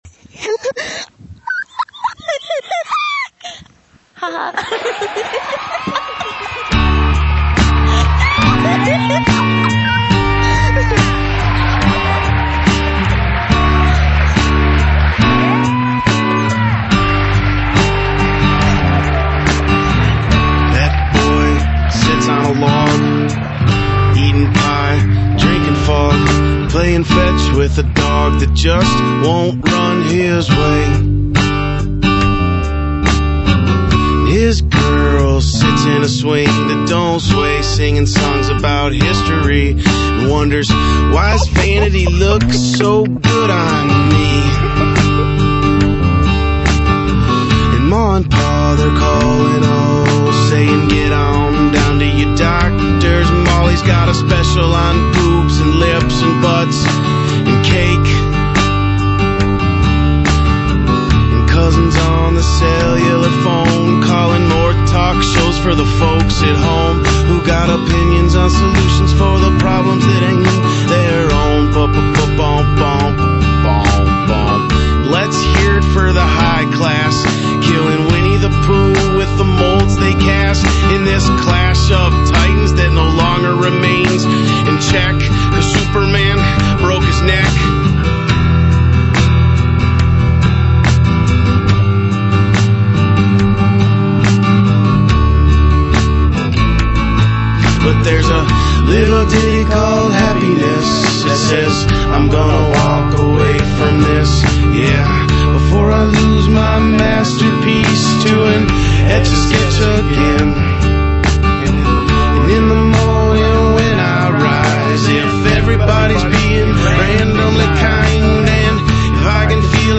He's equally intense on this album.